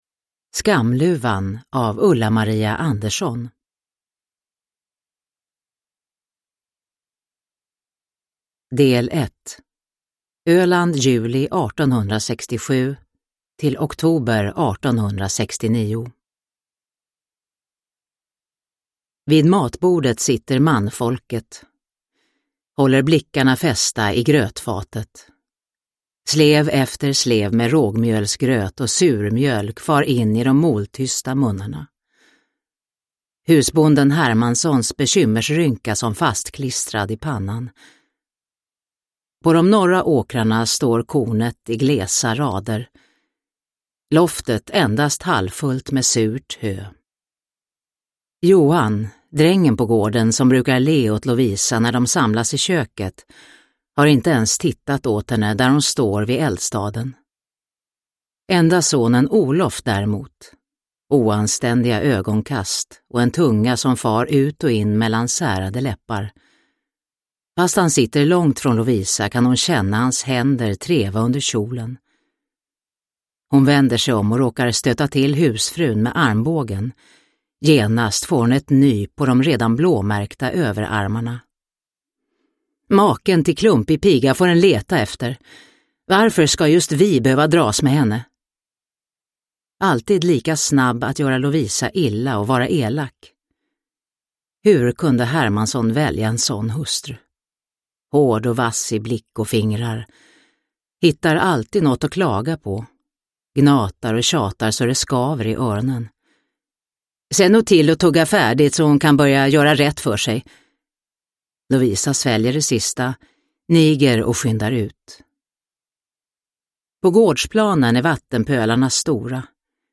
Skamluvan – Ljudbok – Laddas ner